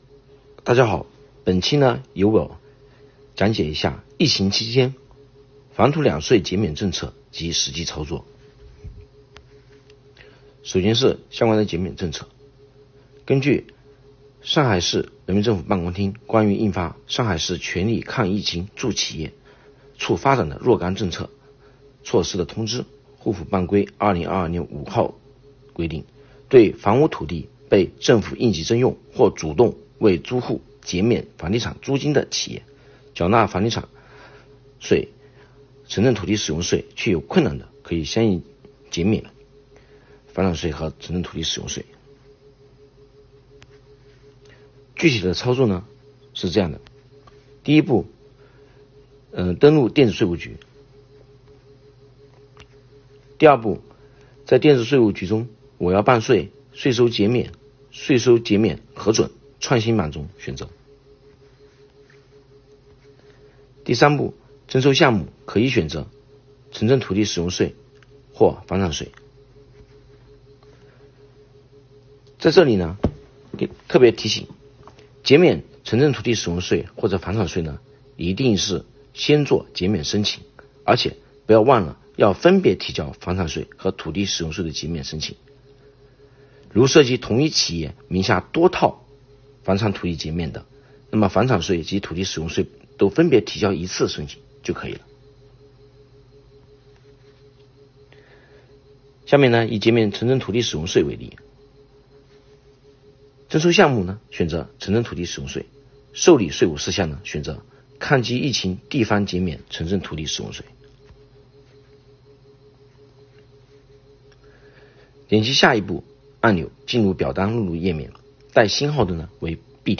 【“嗨！小贤”微课堂】疫情期间房土两税减免政策及实际操作讲解